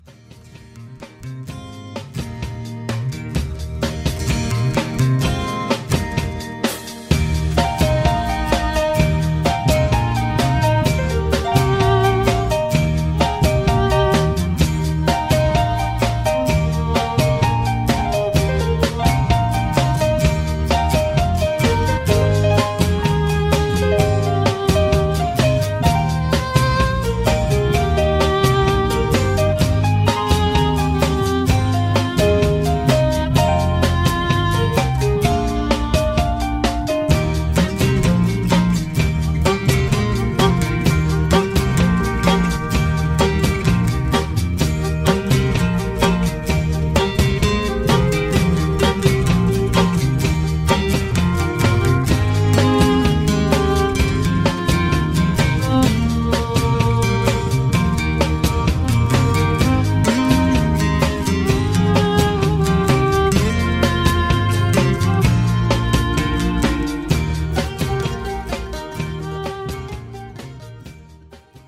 (Low Key)
Singing Calls